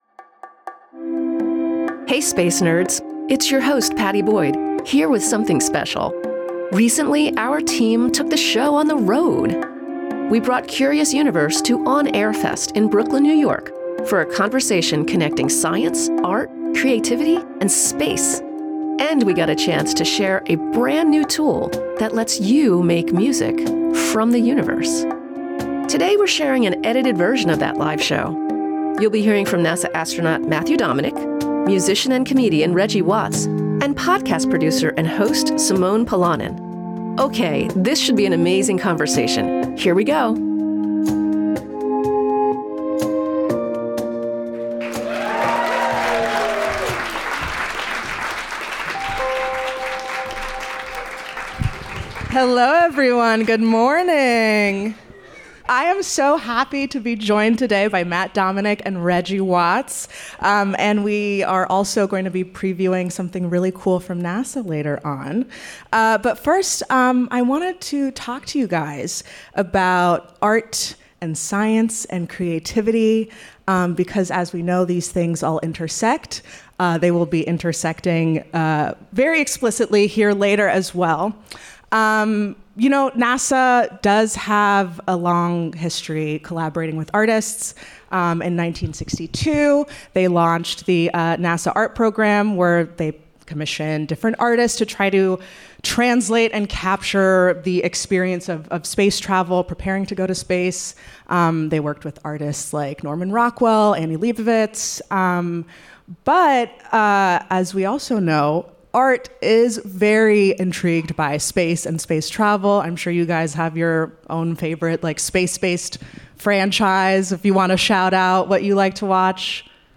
In this special live episode, NASA astronaut Matthew Dominick and comedian and musician Reggie Watts talk flow states, aircraft ejector seats and more. Plus, a new NASA tool that lets you make music from iconic Hubble Space Telescope imagery.